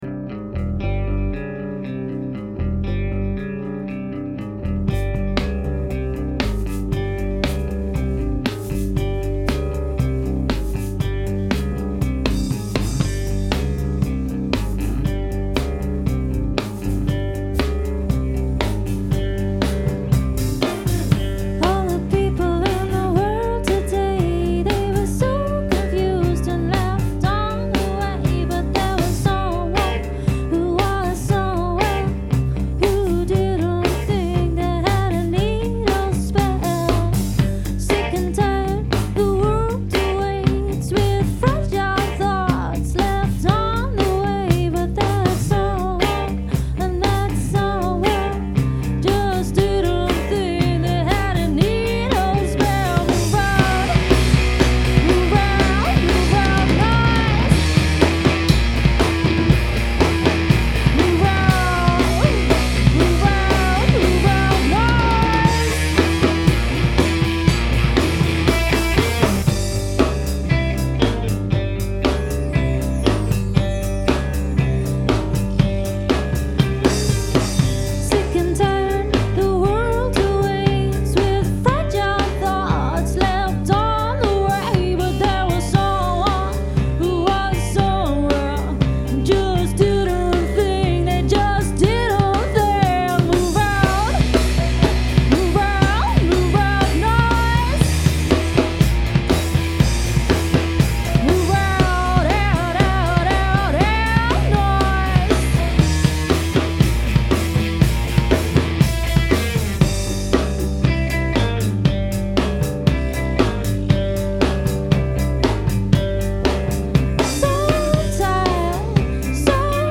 Хотел добиться грубого, плотного звучания.